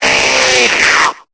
Cri de Chrysacier dans Pokémon Épée et Bouclier.